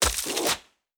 Ice Throw 2.wav